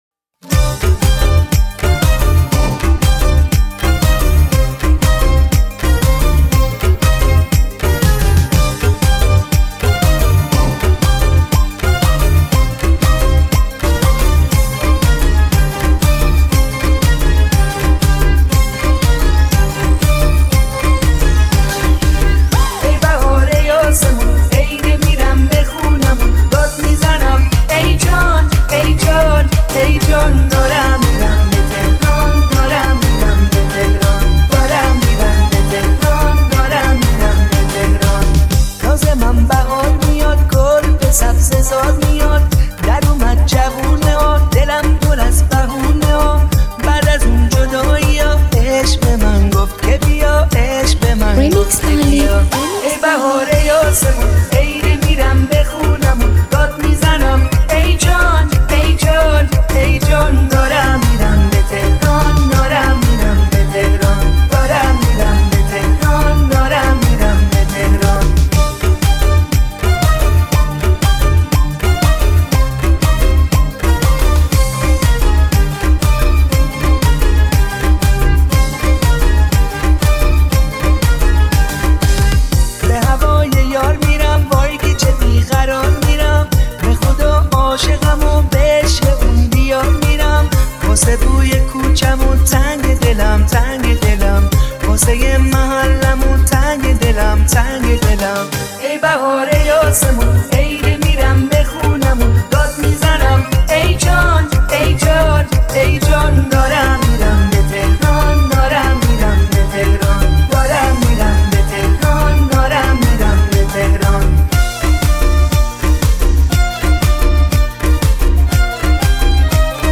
ریمیکس تند ریمیکس بیس دار
ریمیکس شاد